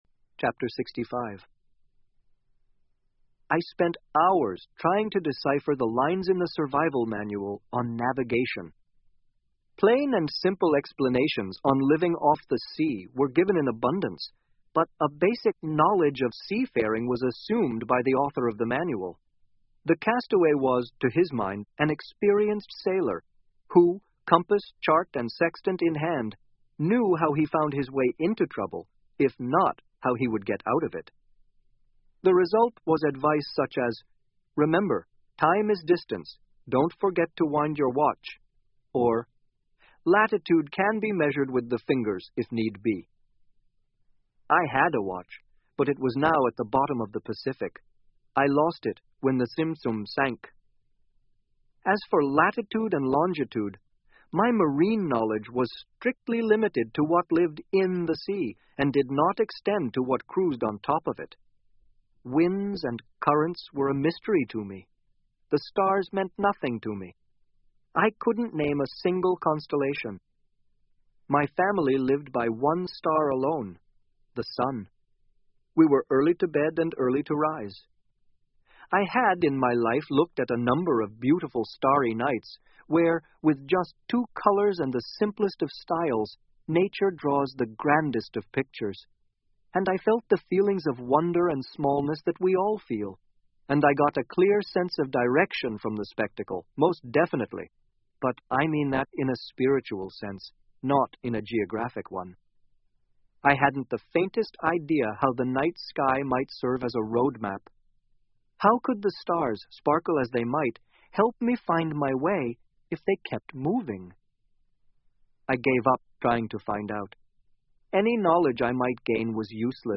英文广播剧在线听 Life Of Pi 少年Pi的奇幻漂流 06-06 听力文件下载—在线英语听力室